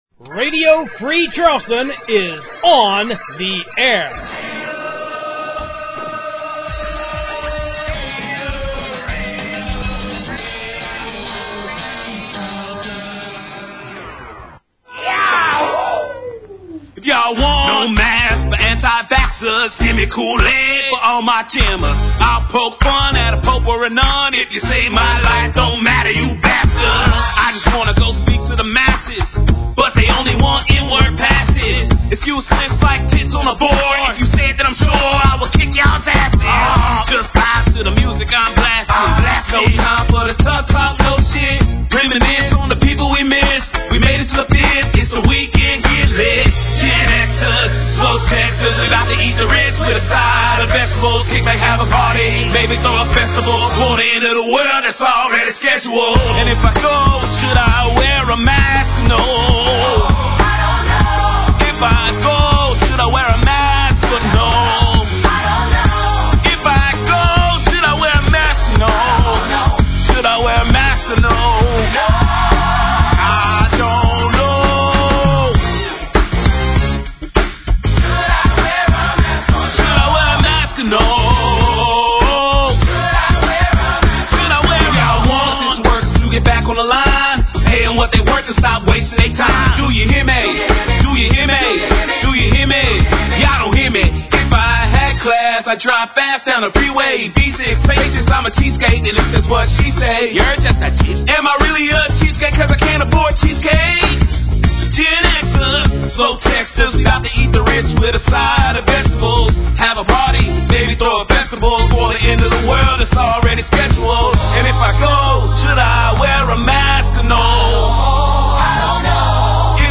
internet radio